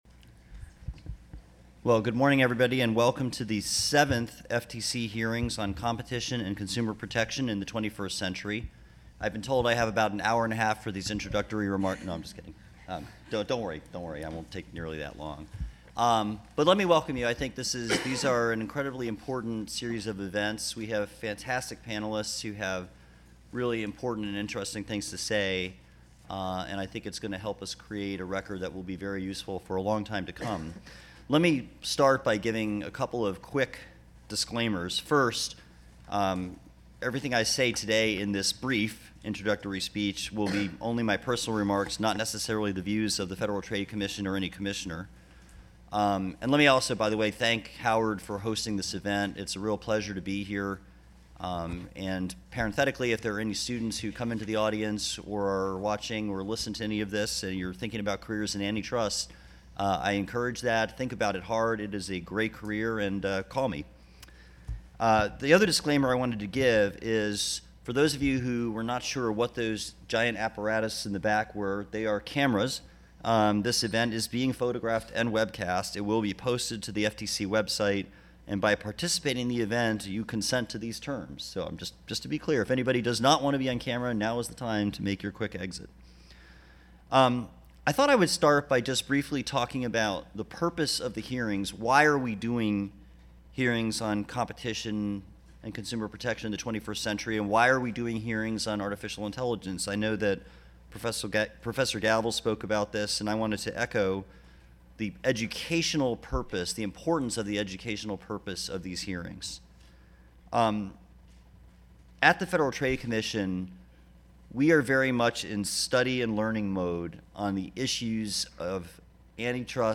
Welcome and Introductory Remarks by BC Director Bruce Hoffman